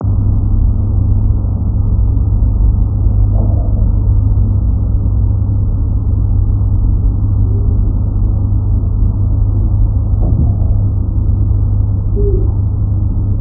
Ich habe oftmals Messungen gemacht und diese dann analysiert, ich stelle an dieser Stelle einmal ein Audio-File ein, welches das Geräusch wiedergibt.
Jedenfalls habe ich bei dem Audio die sehr tiefen und sehr hohen Frequenzen herausgenommen, weil sie nicht in dem Bereich liegen, in dem ich das störende Bereich wahrnehme.
Des Weiteren hört man ein Knacken/Knistern. Das Knistern ist vermutlich irgend ein Mess-/ bzw. Daten-Verarbeitungsproblem, ich habe die Ursache noch nicht gefunden.
Das ist dieses tiefe Brummen, als ich es analysiert habe, bin ich zu der Erkenntnis gekommen, dass es immer hauptsächlich aus zwei Grundfrequenzen besteht, die bei 70 Hz und 170 Hz. liegen.
Das Rauschen (ähnlich wie Meeresrauschen oder so), was ebenfalls auf der ganzen Aufnahme da ist, hat für mich nichts mit dem Störgeräusch zu tun. Ich meine die tiefen Frequenzen und die, die Frequenz, die bei 70 Hz liegt, klingt sehr technisch.